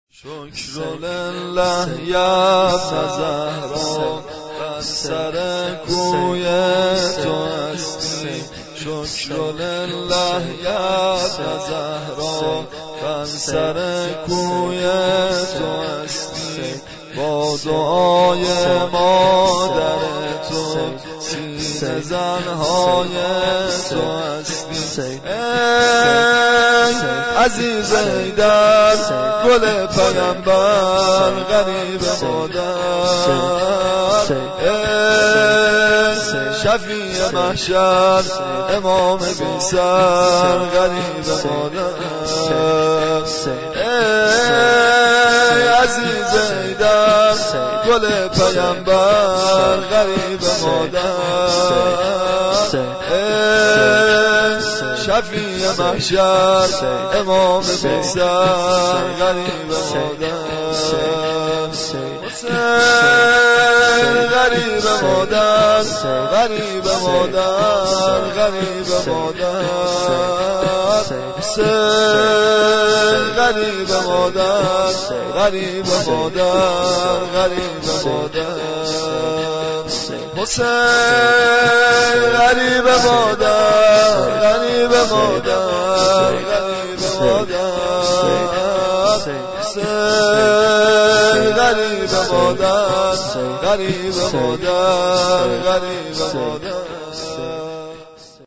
شور امام حسین(ع) -(دنيا بدونه دين و دنيامي)